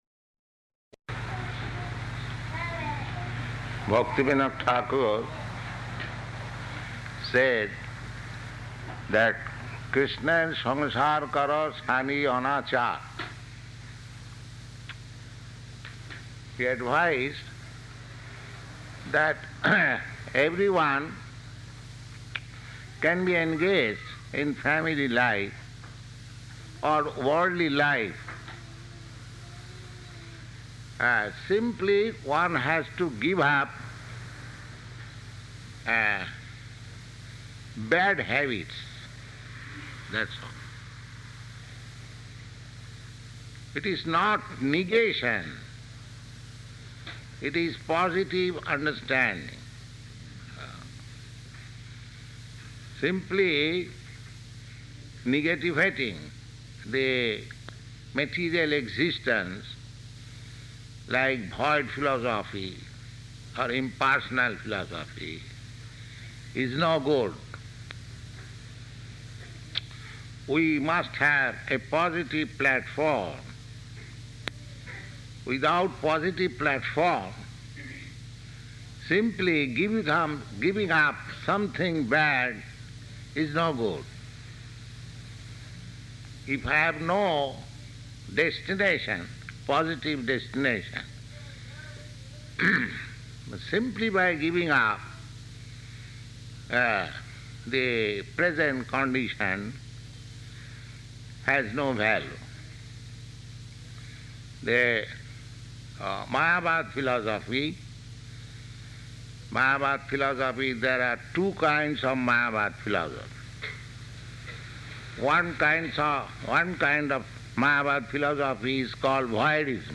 Lecture
Lecture --:-- --:-- Type: Lectures and Addresses Dated: July 12th 1971 Location: Los Angeles Audio file: 710712LE-LOS_ANGELES.mp3 Prabhupāda : Bhaktivinoda Ṭhākura said that kṛṣṇera saṁsāra kara chāḍi' anācāra .